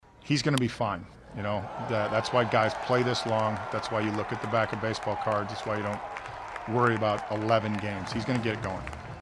Shelton says he’s not concerned with Tommy Pham’s slow start at the plate, especially with what he brings to the team.